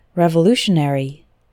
Fast: